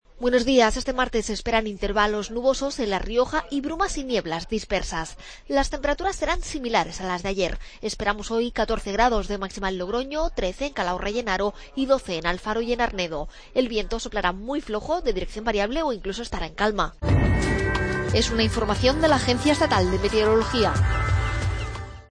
AUDIO: Pronóstico. Agencia Estatal de Meteorología.